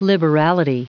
Prononciation du mot liberality en anglais (fichier audio)
Prononciation du mot : liberality